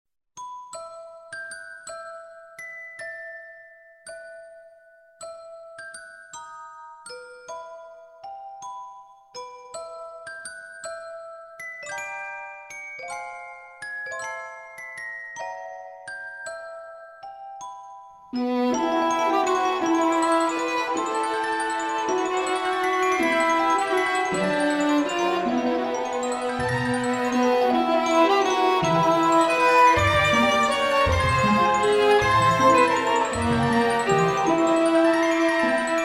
• Качество: 192, Stereo
красивые
спокойные
скрипка
инструментальные